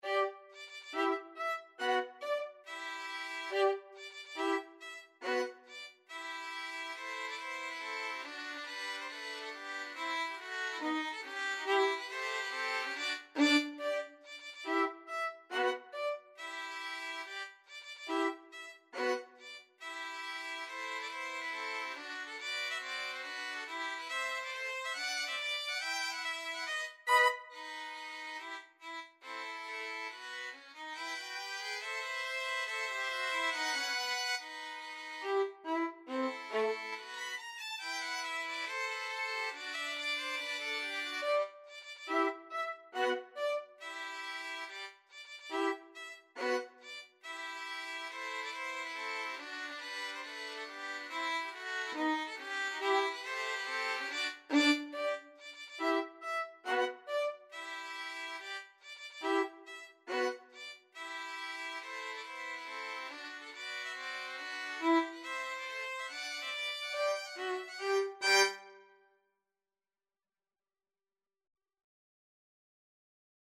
Violin 1Violin 2Violin 3
G major (Sounding Pitch) (View more G major Music for Violin Trio )
Tempo di marcia =140
Instrument:
Classical (View more Classical Violin Trio Music)